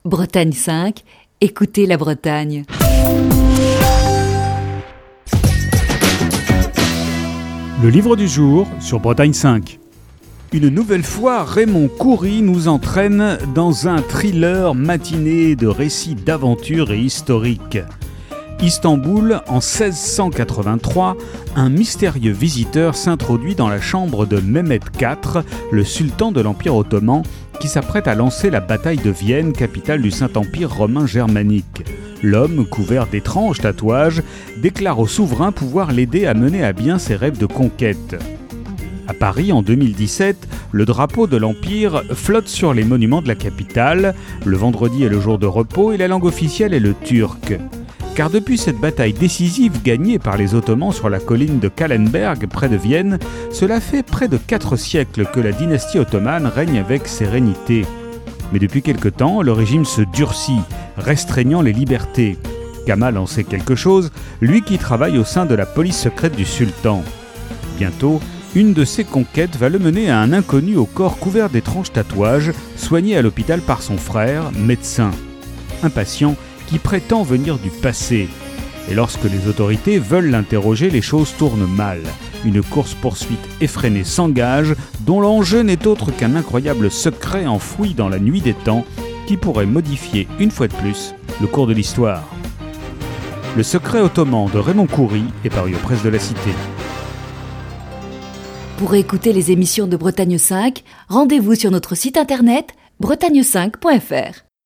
Chronique du 3 juin 2020.